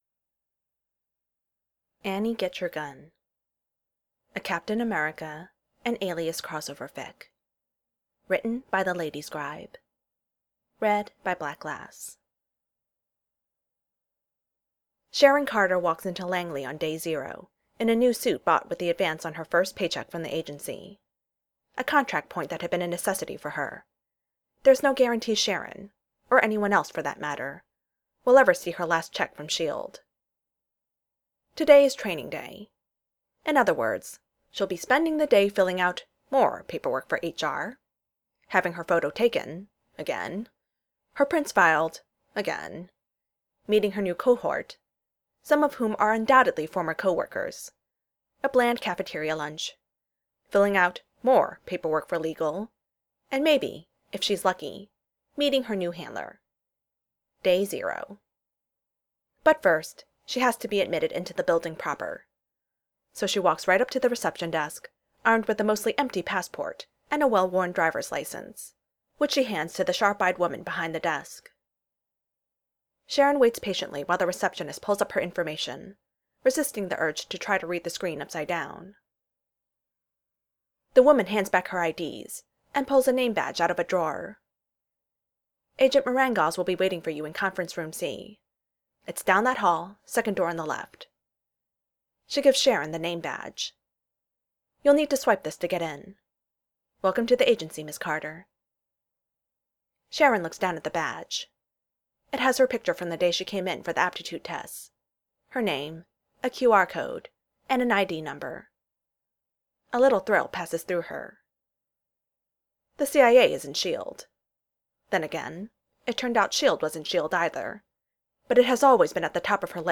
Reader: